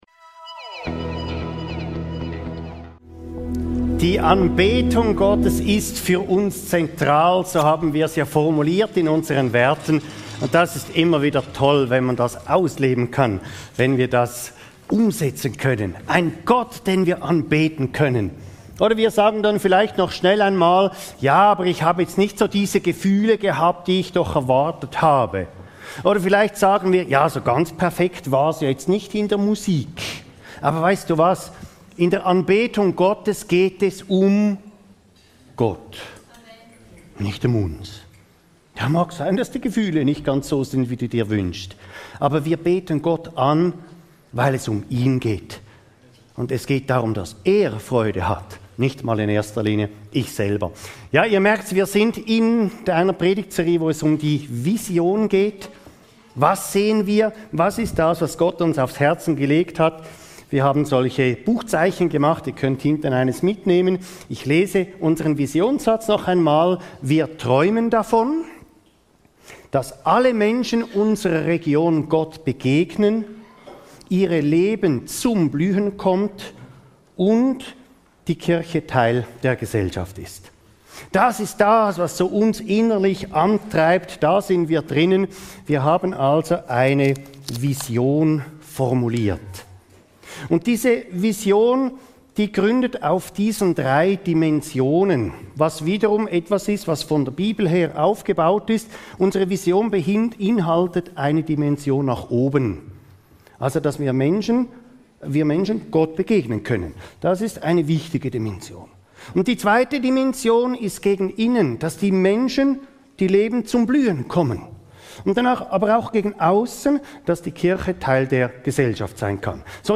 Unsere Vision – Wir lieben Gott ~ Your Weekly Bible Study (Predigten) Podcast